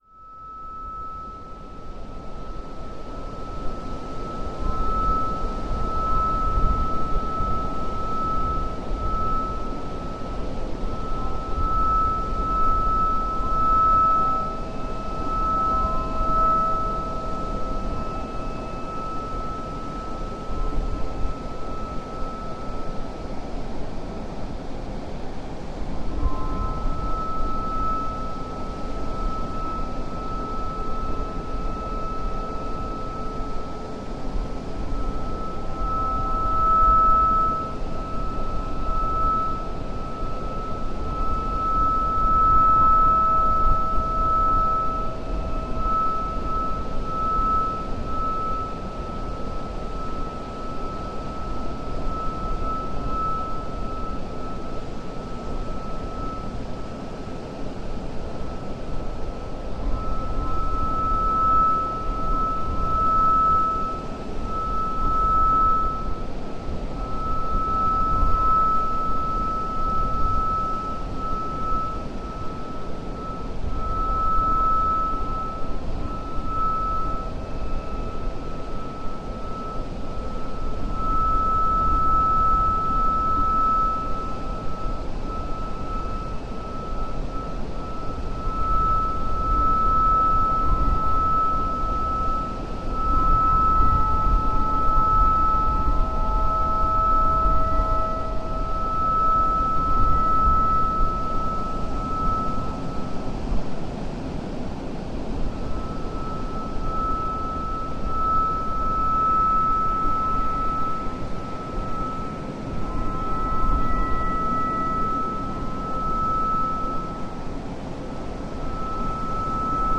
Charango in the wind, Morocco
Charango blowing in the wind to create a strange sound of resonance.